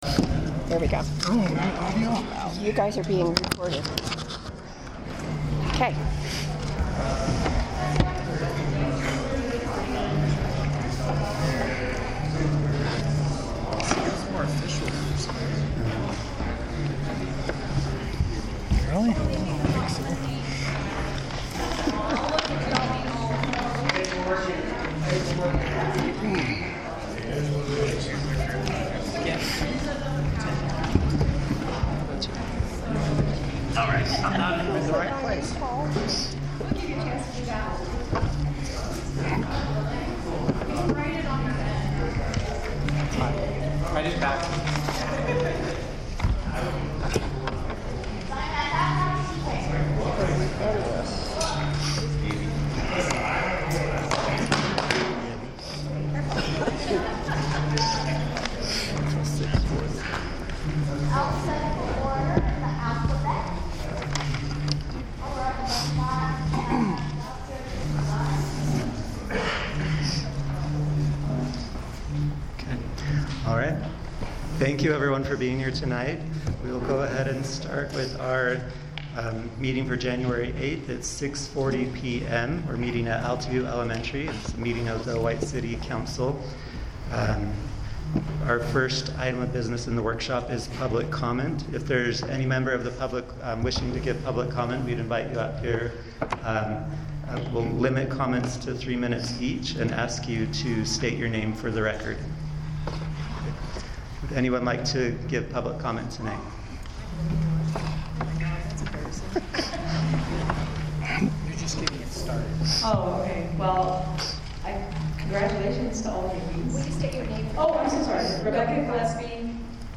Special Council Meeting